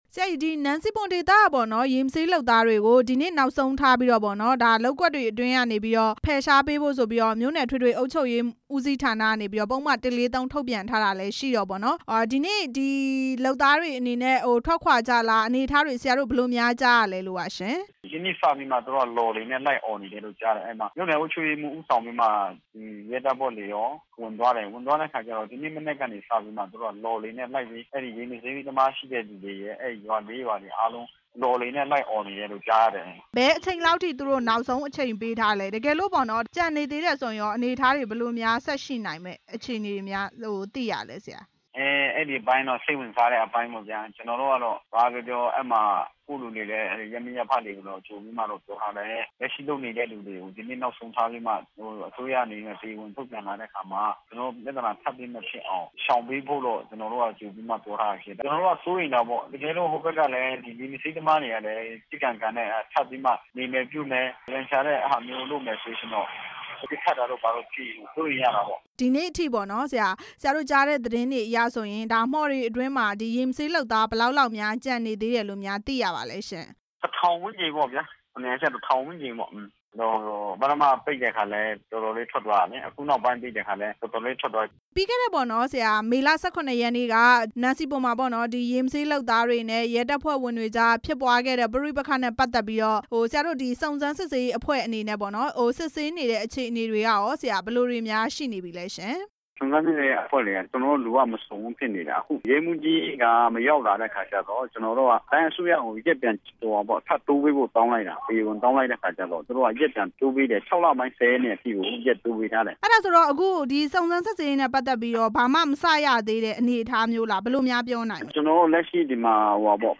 နန်စီပွန်ရေမဆေးသမား အကြောင်း မေးမြန်းချက်